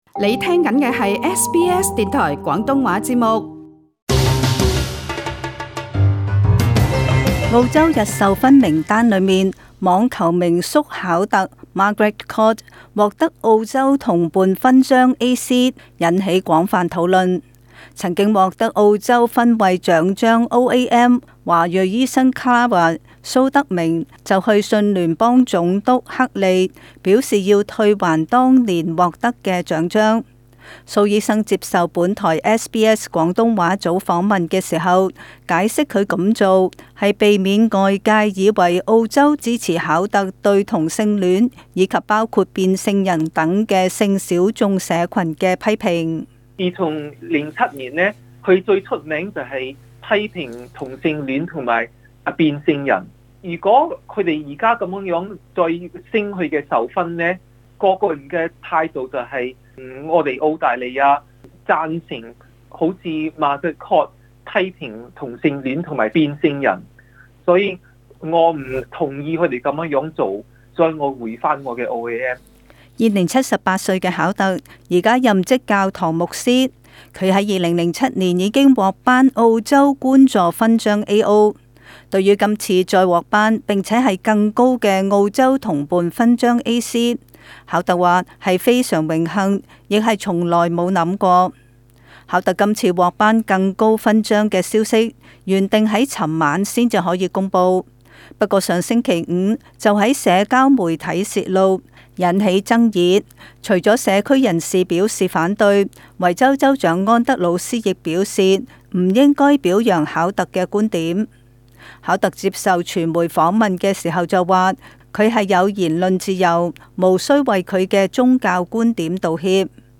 【專訪】反同性戀網球名宿獲勳 華裔醫生退還OAM抗議